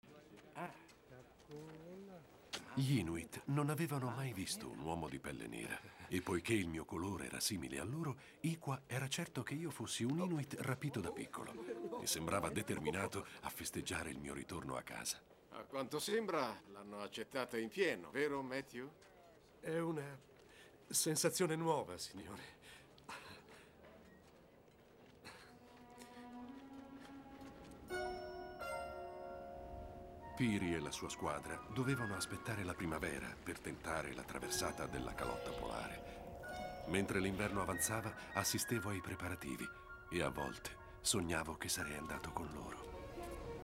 TV "Glory & Honor", in cui doppia Delroy Lindo.